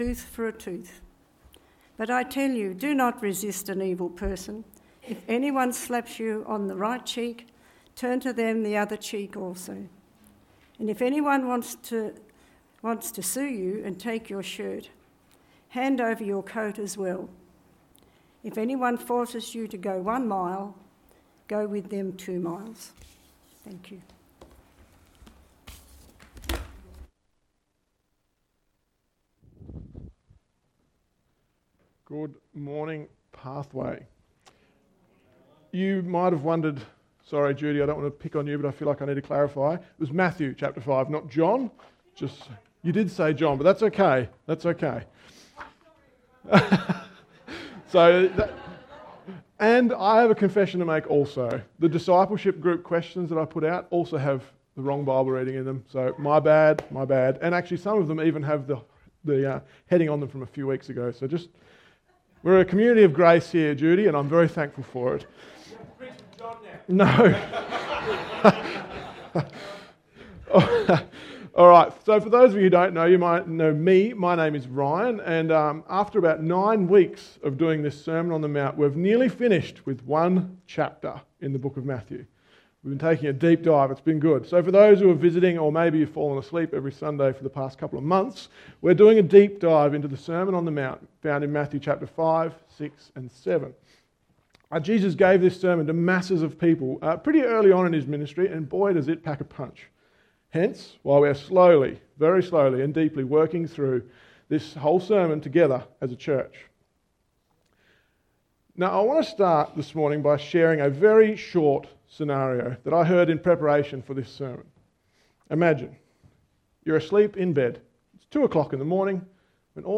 Text: Matthew 5: 38-42 Sermon